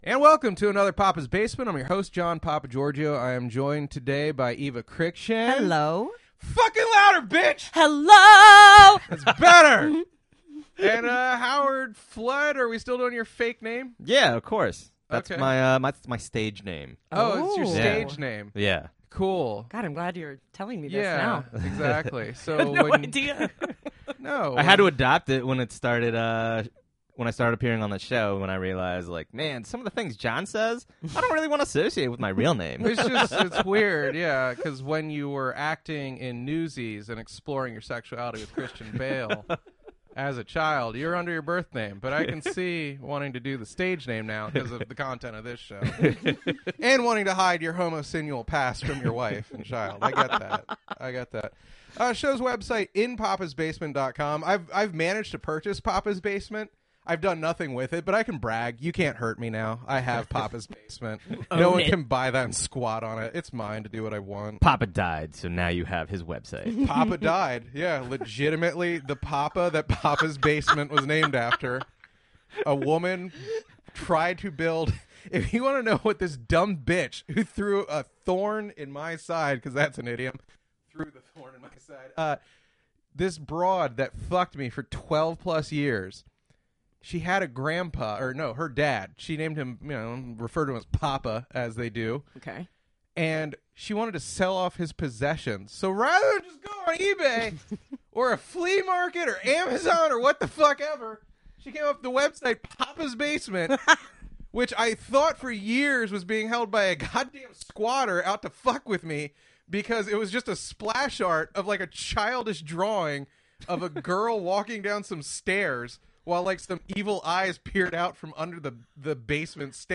Some childhood friends share the mic with me and make a compelling argument (through really embarrassing anecdotes) that I’m probably somewhere on the spectrum.